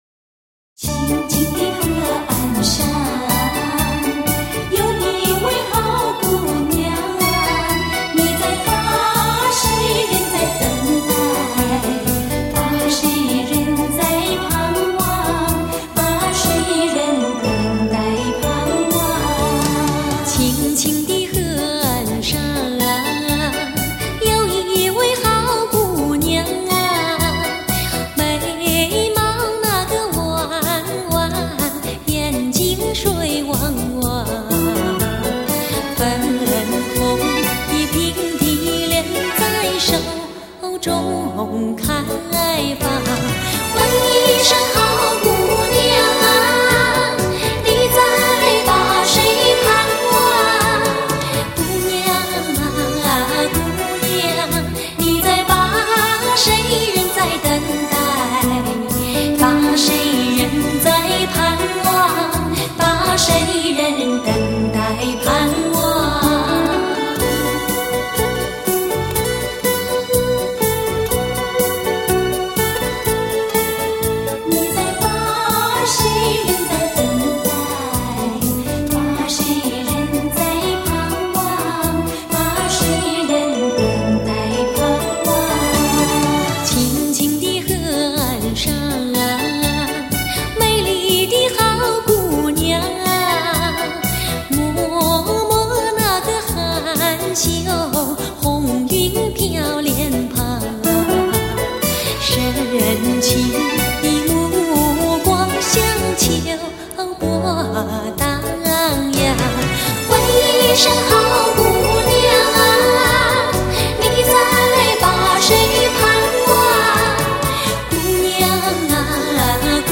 浓情老歌